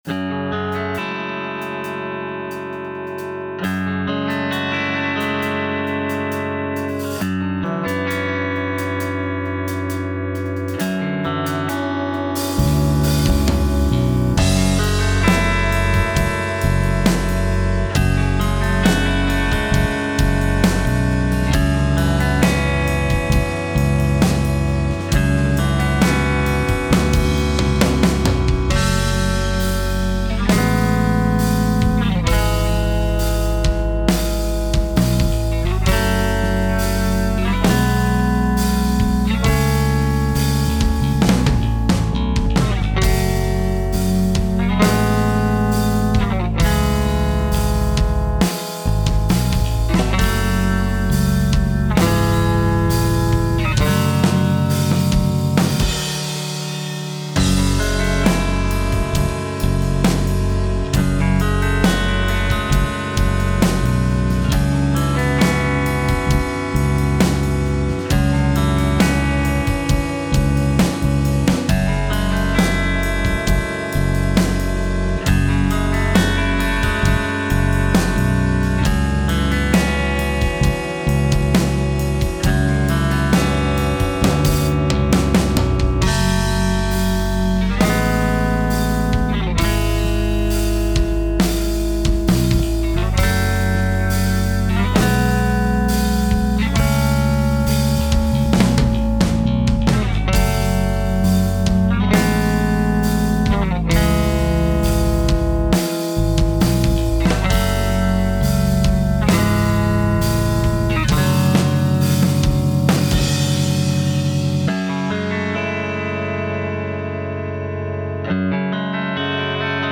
タグ: バンド ロック 幻想的 コメント: 夜の水辺の風景をイメージした楽曲。